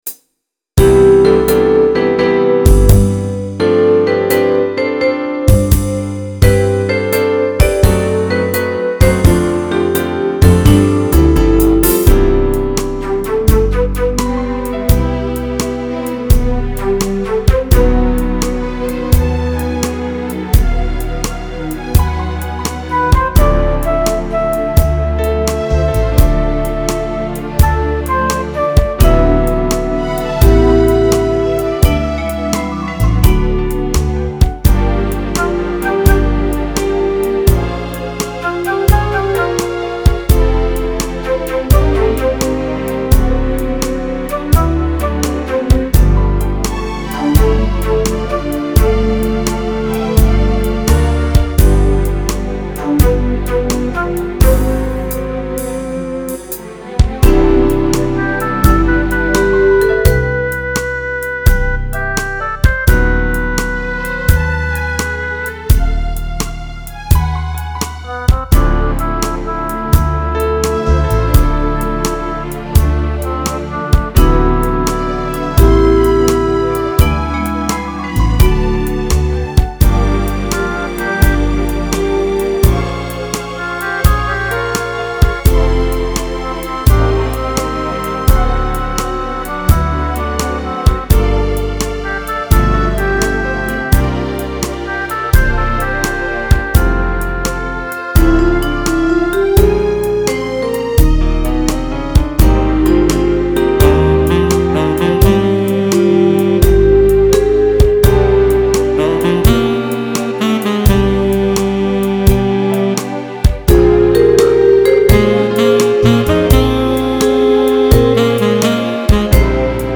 thD36H2YGV  Download Instrumental